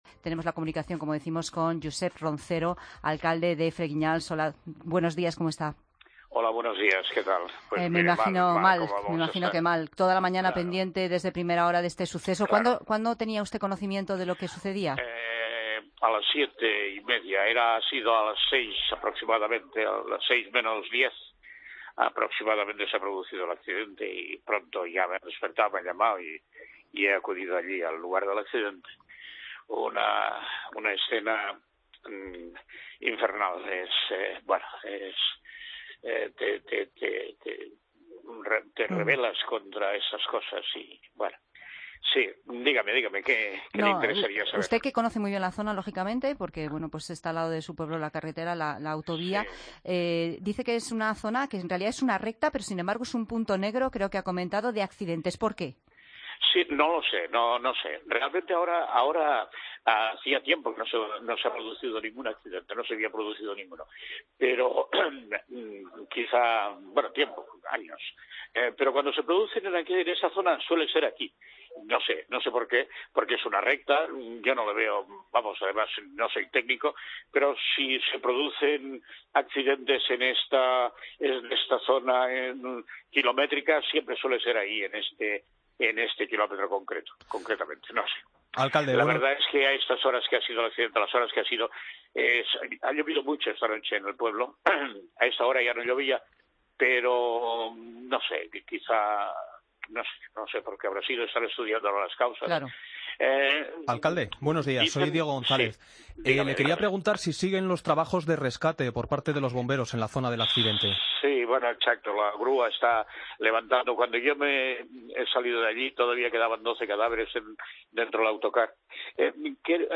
Entrevista a Josep Roncero, alcalde de Fraginals, en Fin de Semana COPE.